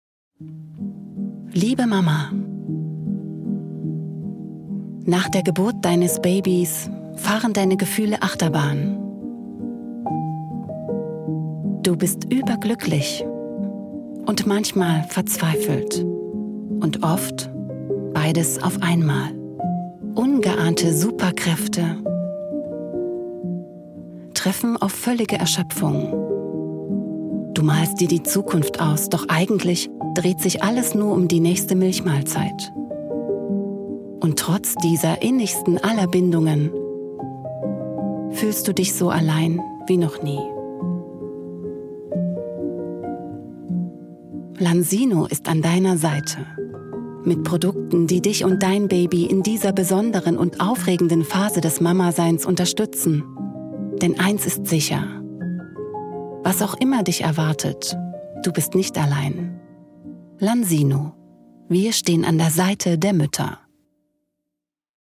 Erfahrene Profisprecherin
Warme Werbestimme